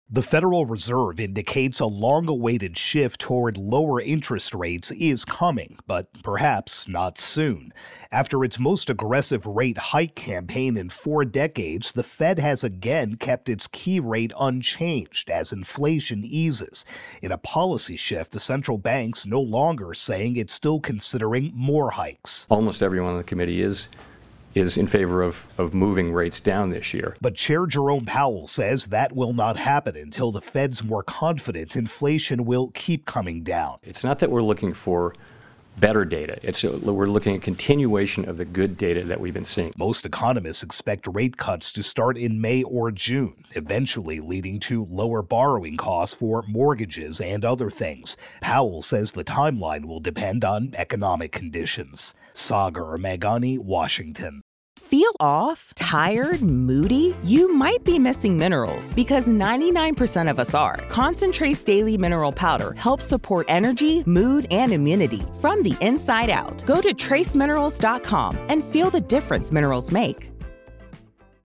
reports on Federal Reserve.